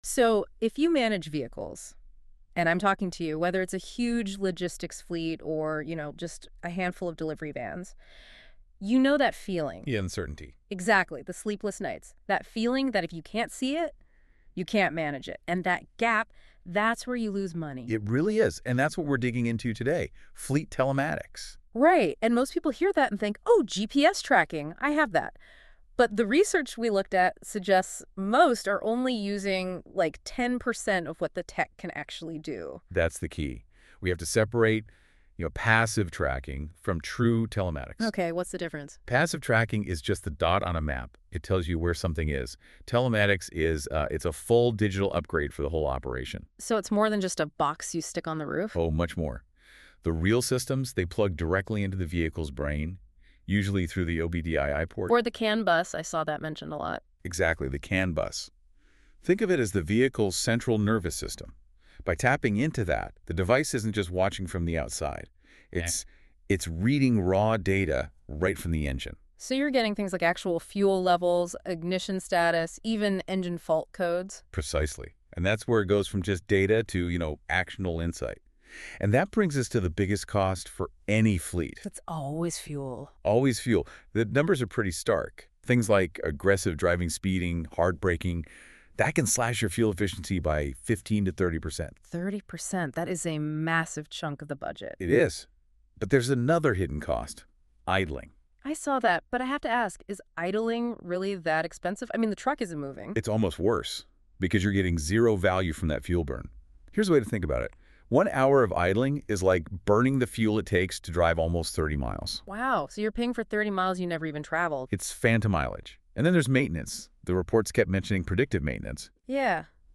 Prefer listening? Press play below to hear the AI-generated podcast version of this article.